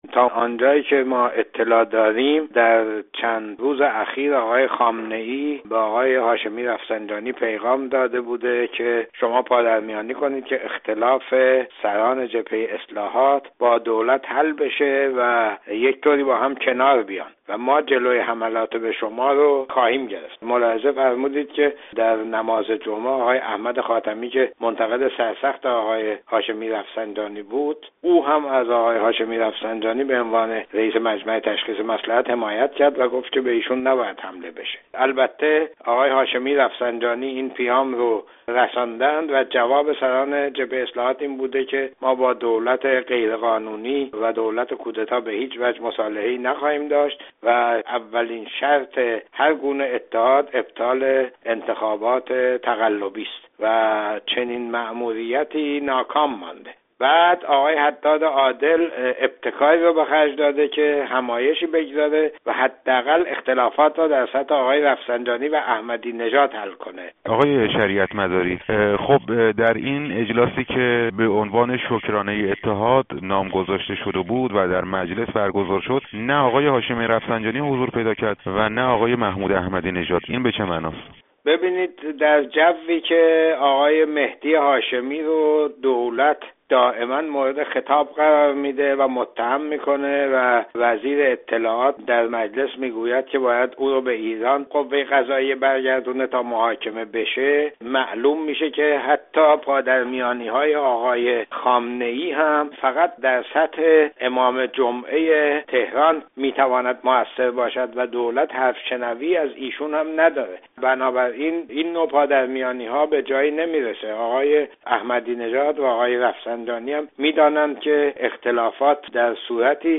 اجلاس «شکرانه [بی] اتحاد»؟ گفت‌وگو